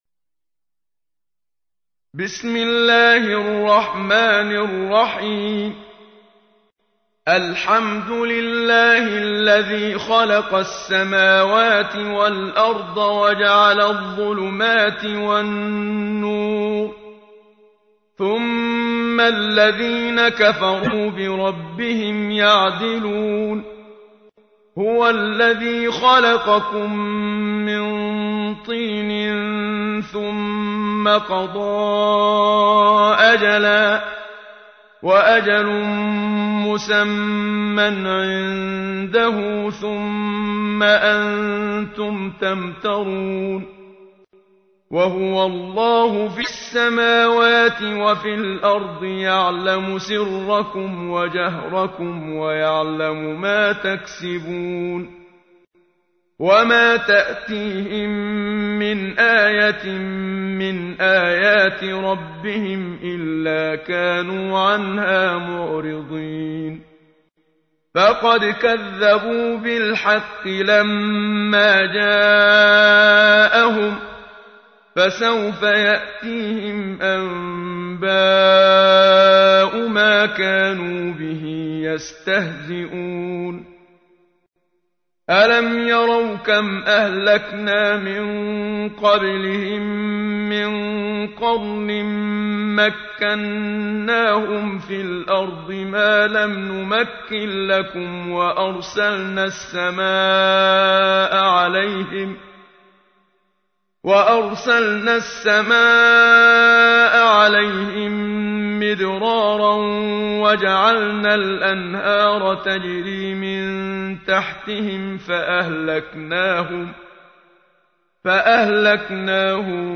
تحميل : 6. سورة الأنعام / القارئ محمد صديق المنشاوي / القرآن الكريم / موقع يا حسين